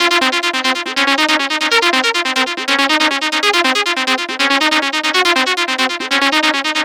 TSNRG2 Lead 024.wav